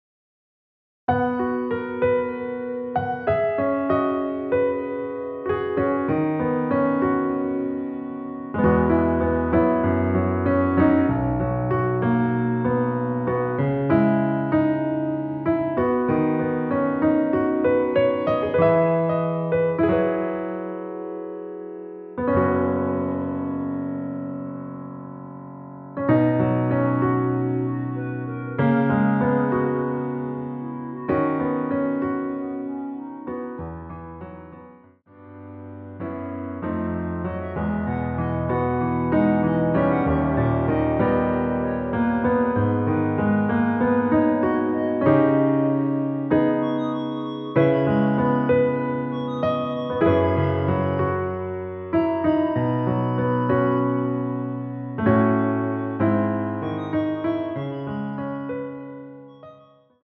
원키에서(+1)올린 멜로디 포함된 MR입니다.
앞부분30초, 뒷부분30초씩 편집해서 올려 드리고 있습니다.
중간에 음이 끈어지고 다시 나오는 이유는